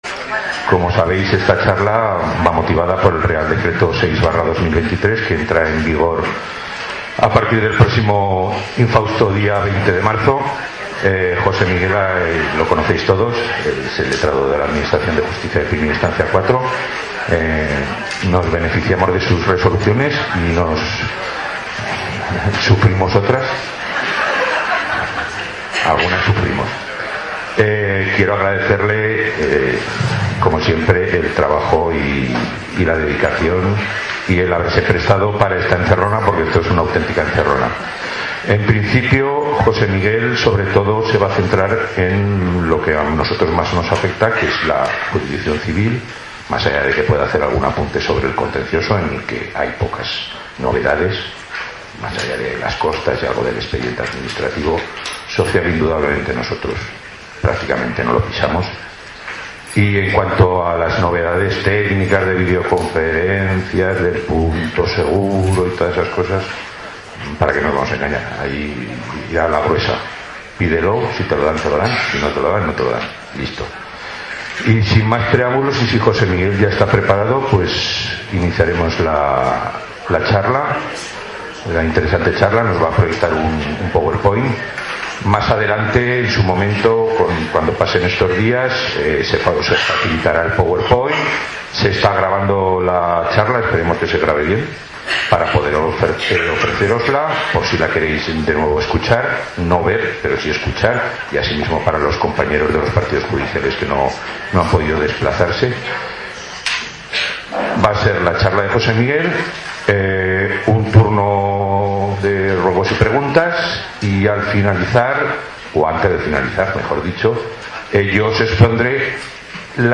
Audio charla RDL 6-23 - 1.ª parte